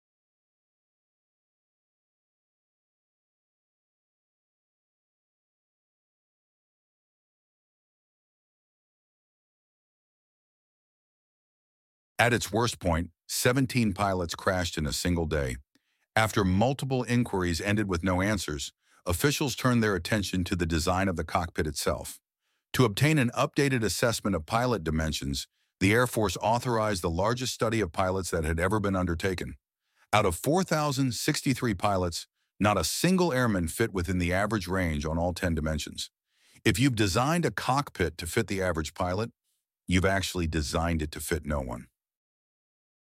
alternate VO plagiarized from the plagiarism machine